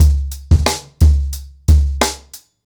TrackBack-90BPM.29.wav